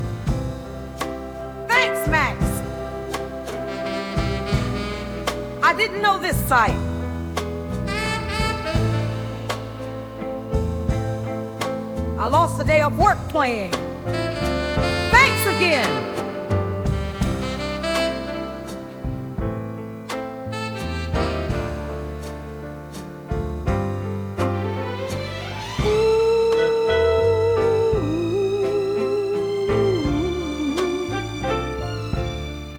Tumult Hip Hop inspired by your song.